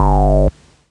cch_bass_one_shot_under_G.wav